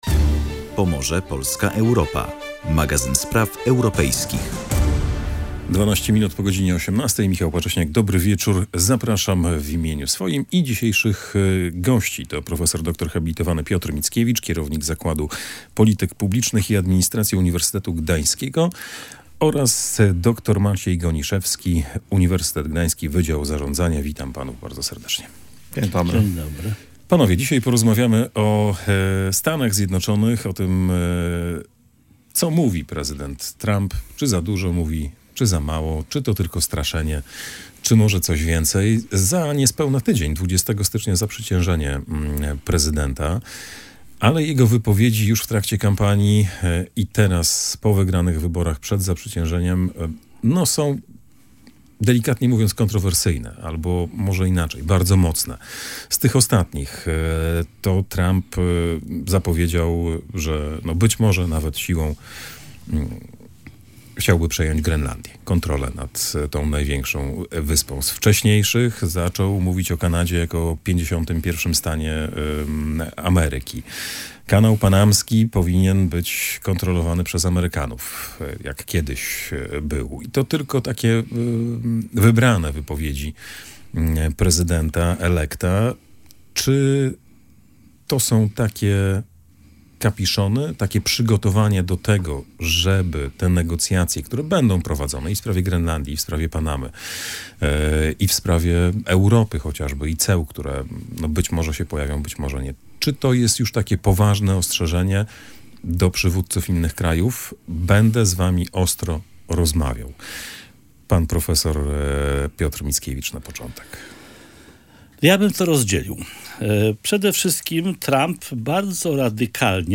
Jak będzie wyglądała polityka zagraniczna Stanów Zjednoczonych? Czy należy się obawiać zapowiedzi Trumpa? Jakie znaczenie dla USA ma Europa? O tym rozmawialiśmy w programie „Pomorze, Polska, Europa”.